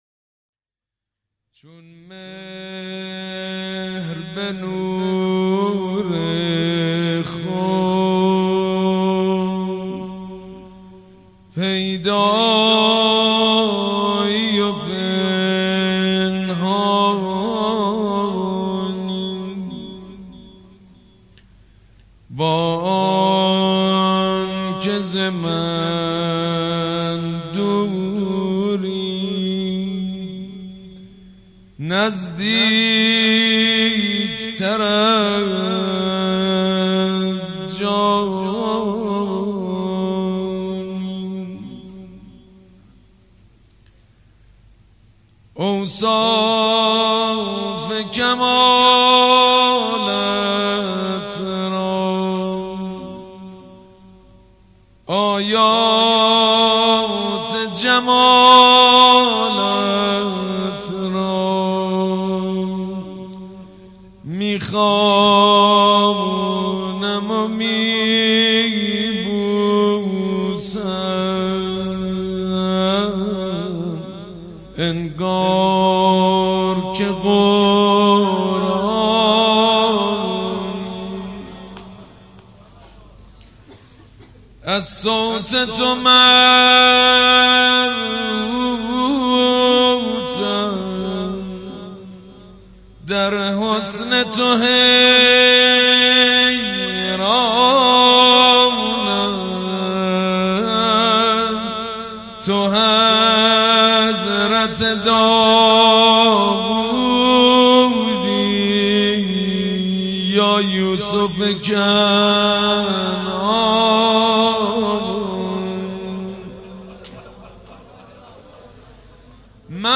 دانلود مداحی جدید شهادت امام جعفر صادق
مناجات با امام زمان